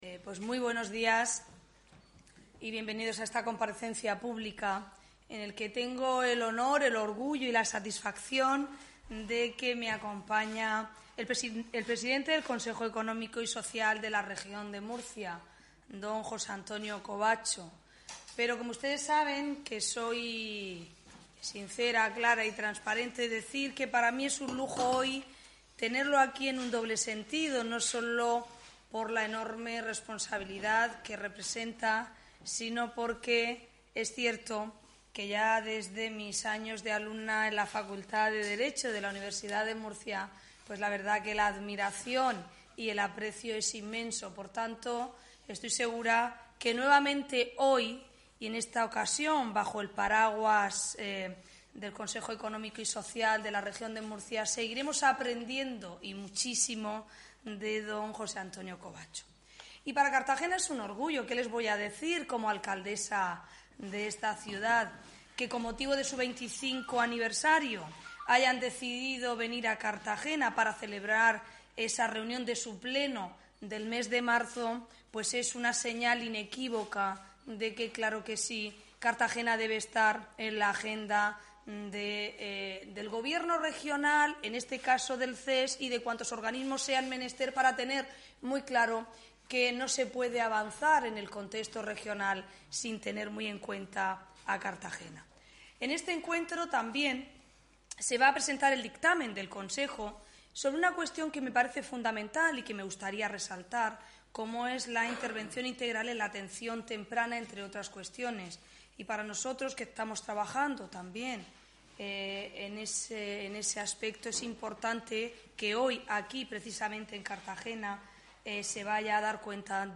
Audio: Rueda de prensa sobre pleno del Consejo Econ�mico y Social (MP3 - 8,98 MB)